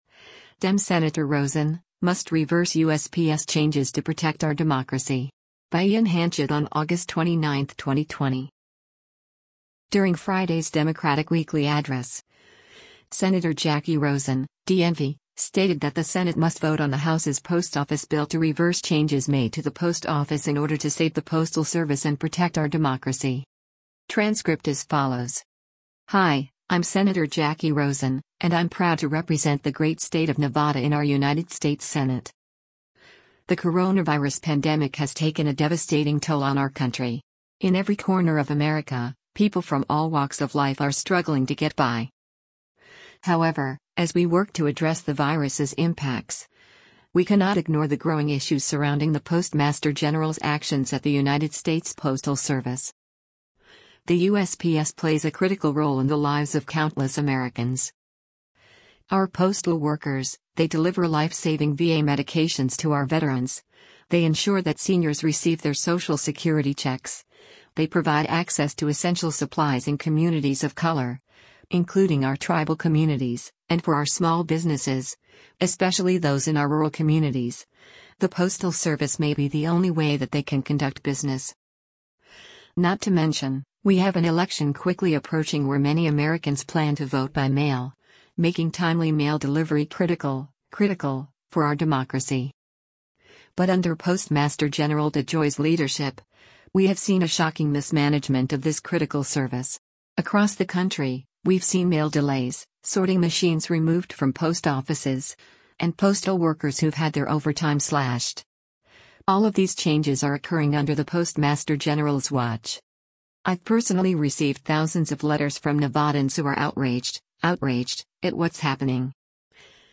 During Friday’s Democratic Weekly Address, Sen. Jacky Rosen (D-NV) stated that the Senate must vote on the House’s Post Office bill to reverse changes made to the Post Office “in order to save the Postal Service and protect our democracy.”